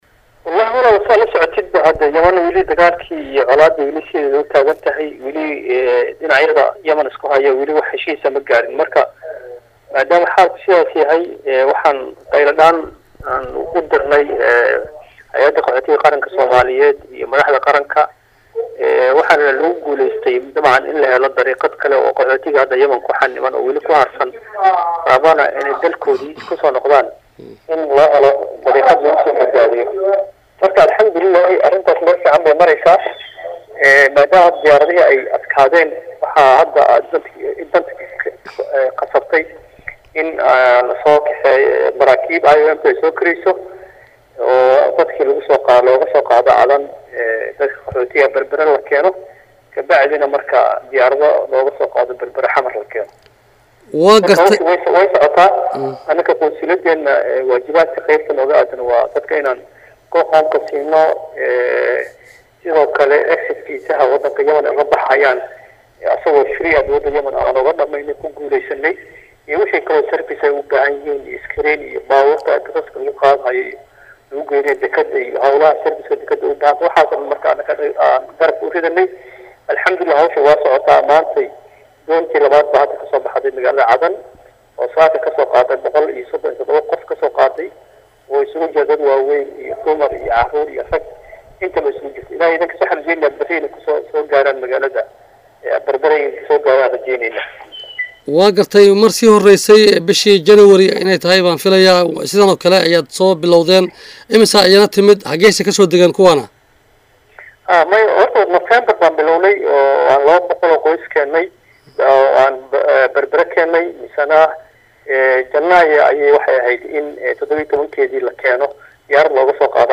WAREYSI-QUNSULKA-GUUD-EE-CADAN-MUD-AXMED-CABDI-XASAN.mp3